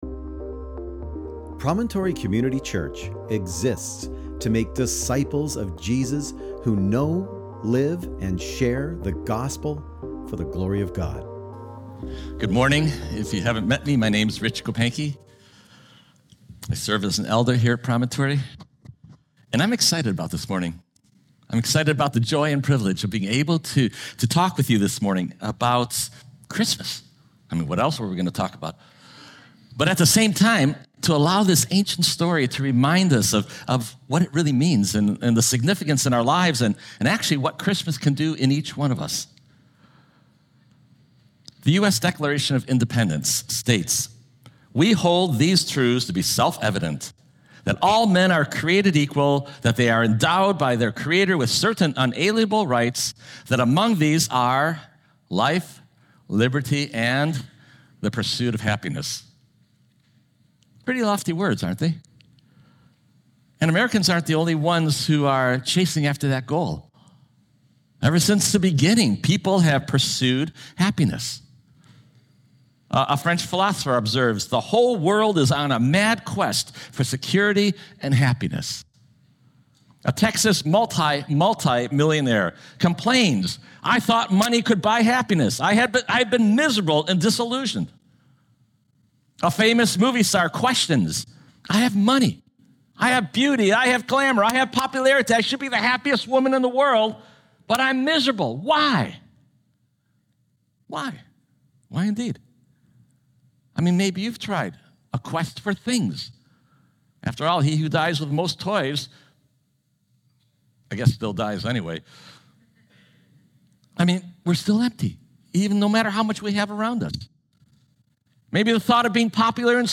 Sermon Text: Luke 1:67-79